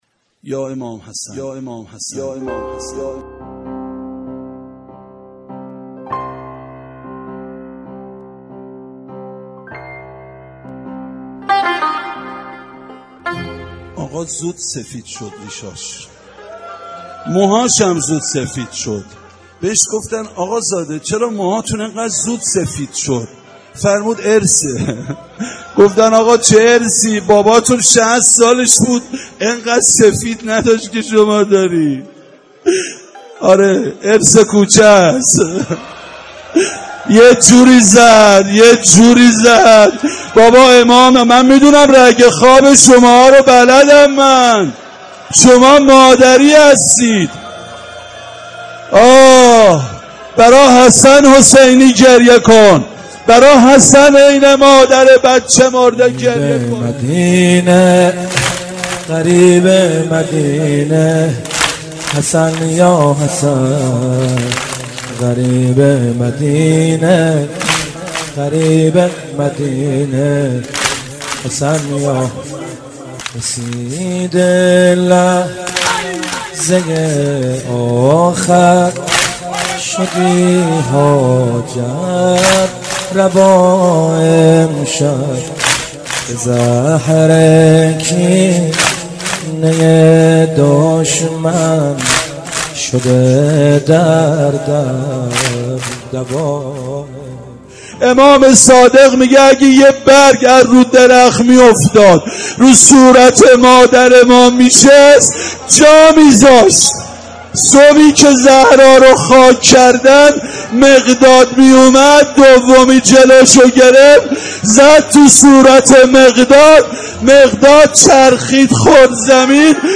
روضه جانسوز امام حسن علیه السلام